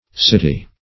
City \Cit"y\ (s[i^]t"[y^]), n.; pl. Cities (s[i^]t"[i^]z).